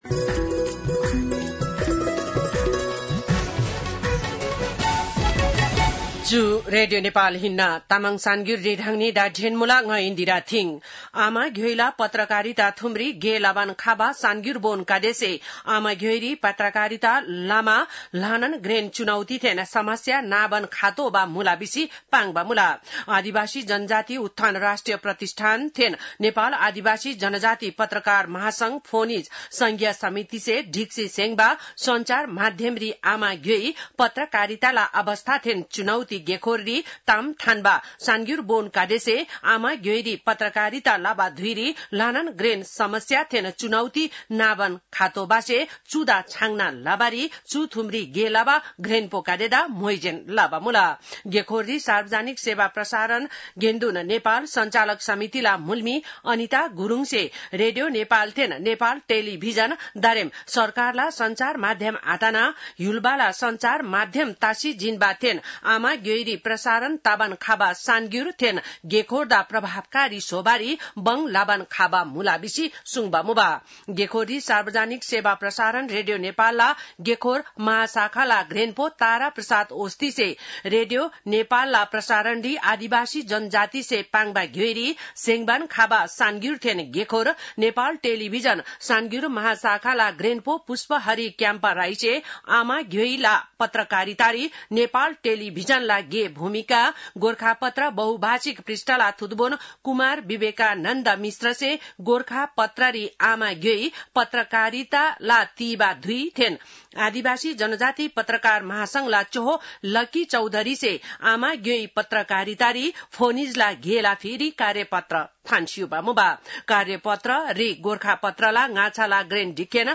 तामाङ भाषाको समाचार : १३ असार , २०८२